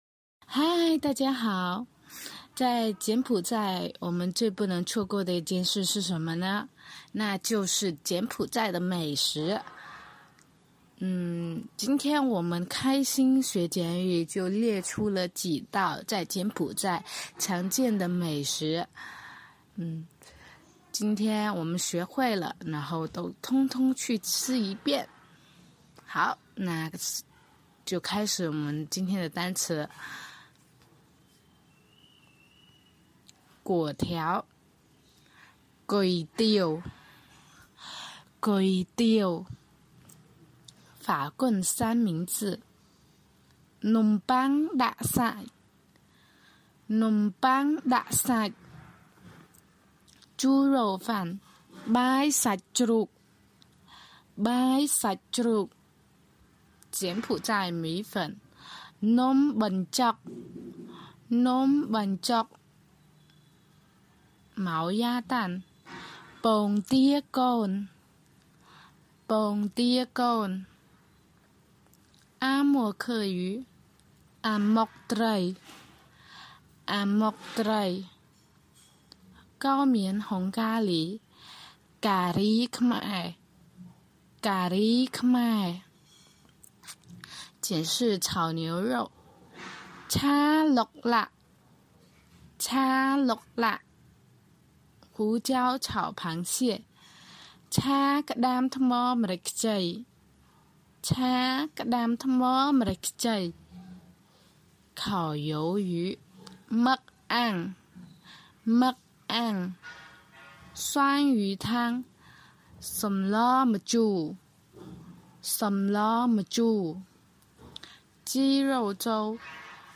柬埔寨|柬单网|柬埔寨,美食,西哈努克-本期主题—美食单词▼-粿条 គុយទាវ（读作：归丢）柬埔寨最常见的早餐之一，粿条加上熬煮好的汤，加上豆芽，柠檬、胡椒，再蘸点酱料吃，特别有满足感。